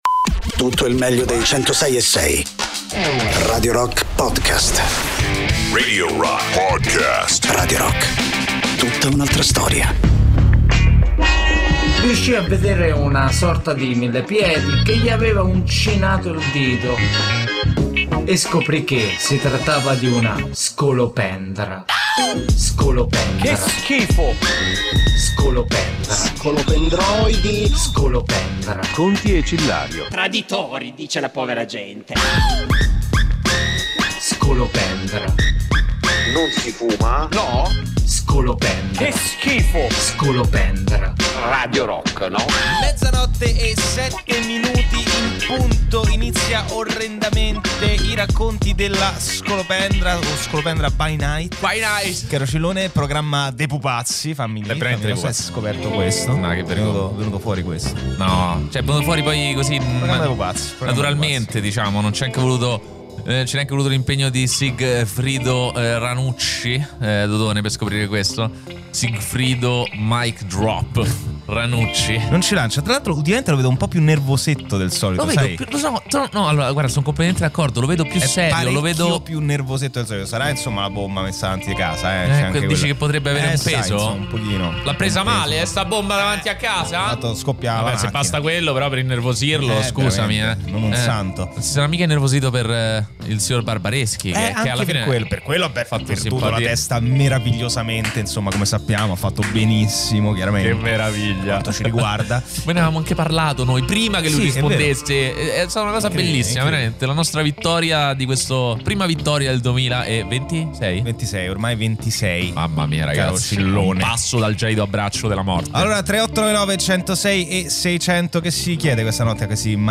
da Lunedì a Giovedì da mezzanotte all’1 con “La Skolopendra By Night” sui 106.6 di Radio Rock